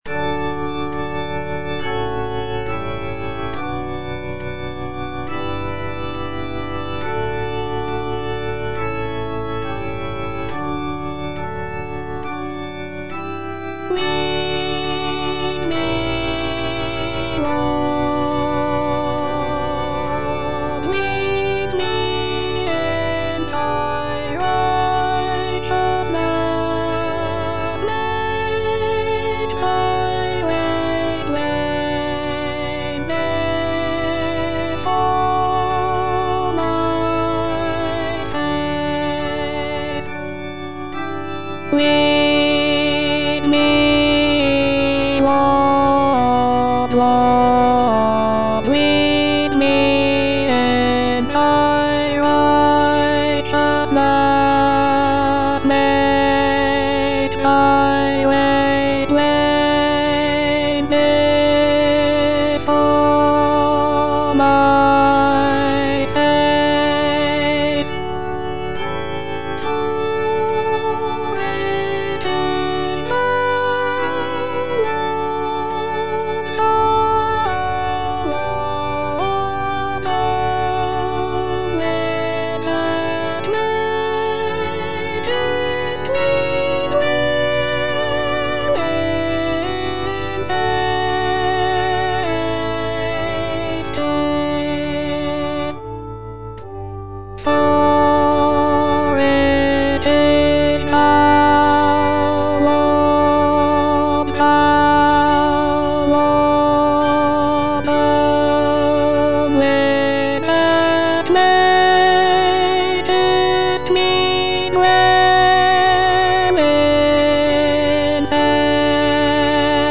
S.S. WESLEY - LEAD ME, LORD Alto (Voice with metronome) Ads stop: auto-stop
"Lead me, Lord" is a sacred choral anthem composed by Samuel Sebastian Wesley in the 19th century.
The music is characterized by lush choral textures and expressive dynamics, making it a popular choice for church choirs and worship services.